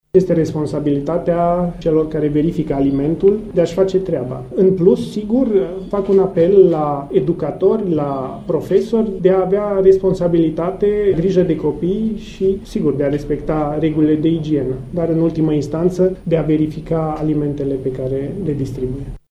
Ministrul sănătăţii, Patriciu Achimaş-Cadariu, a declarat, azi, la Bistrița că anchetaa urmează protocolul stabilit de Ministerul Sănătaţii și cu Centrul European de Control al Bolilor.